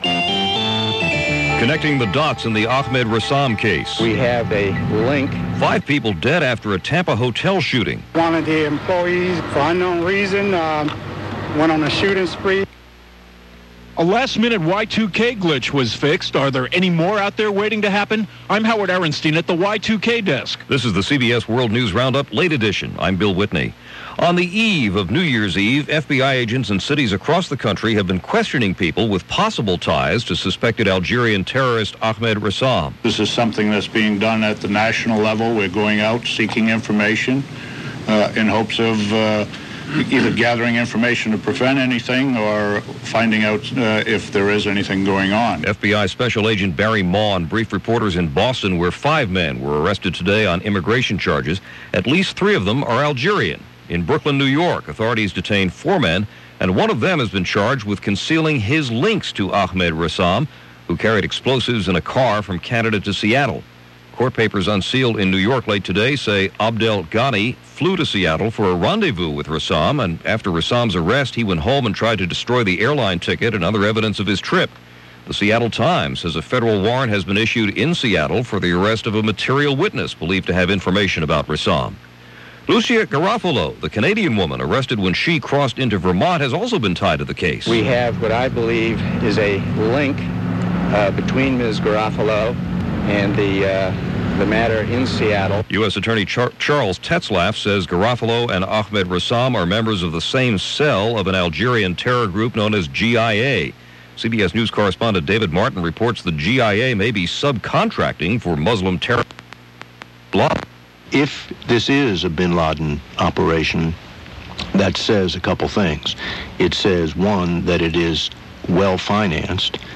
All that, and a lot more for this next-to-last day of the 20th century via The CBS World News Roundup; Late Edition.